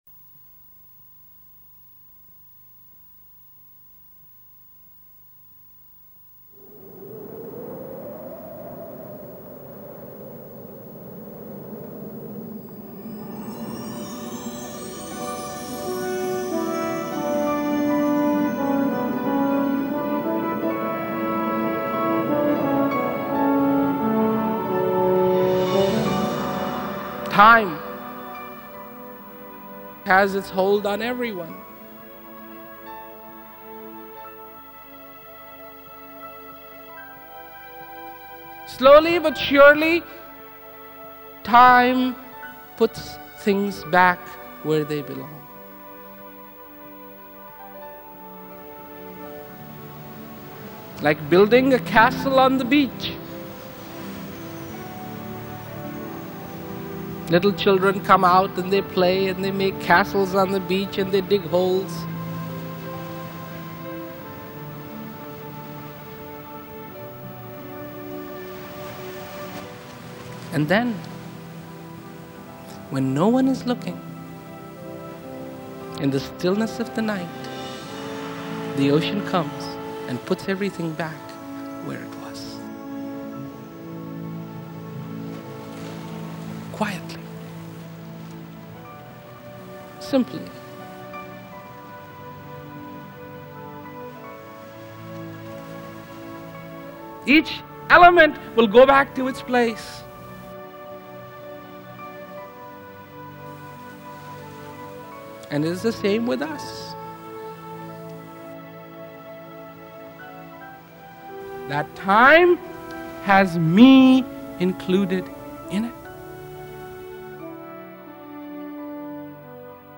It was a combination of songs recorded by some of his followers and two sections of Rawat's speeches with background music that attempts to enhance Rawat's message.
Mp3 copies of these excerpts are recorded at high quality (256Kbps) to ensure no whispered nuance or frenzied climax is missed.